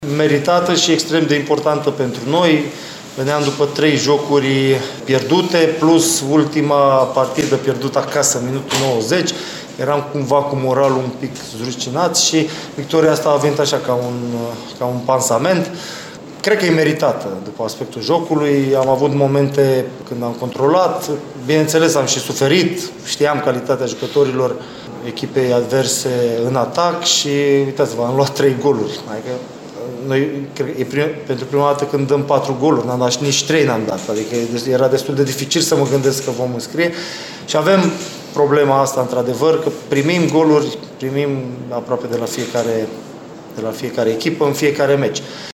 În urma acestui rezultat Slobozia urcă temporar pe locul 13, cu 21 de puncte, tot atâtea câte au în acest moment UTA, Farul și Politehnica Iași. Antrenorul trupei din Bărăgan, Adrian Mihalcea, remarcă tocmai importanța acestui rezultat: